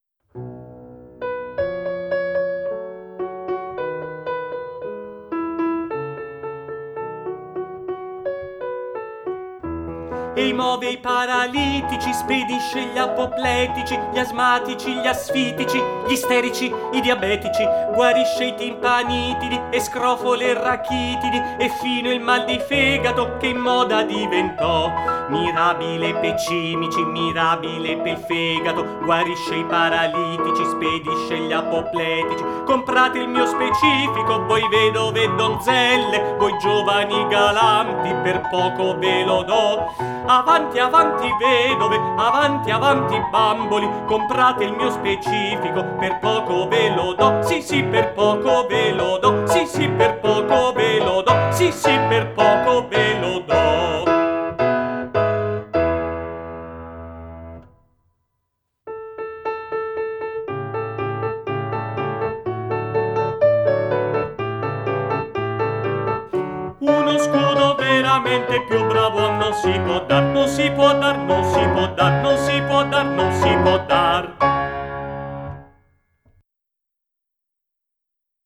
16_Cavatina di Dulcamara_cantata
16_Cavatina-di-Dulcamara_cantata.mp3